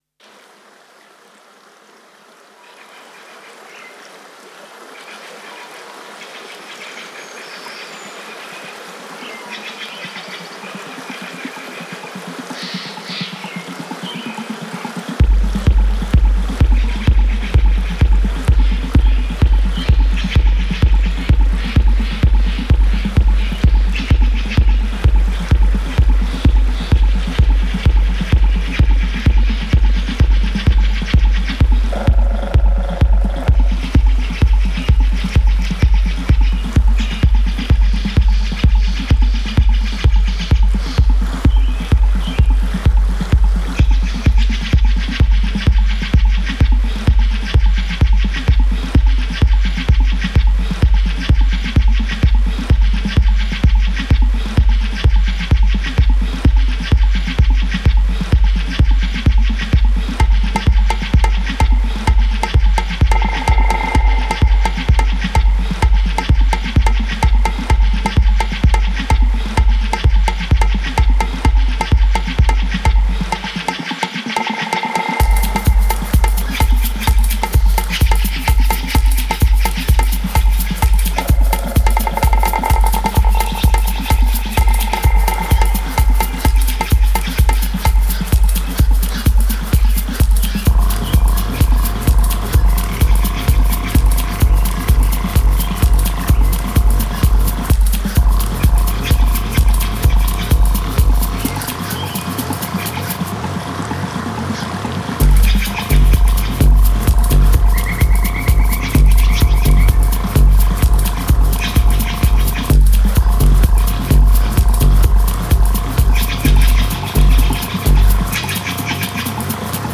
Techno is alive in the woods of western Scandinavia.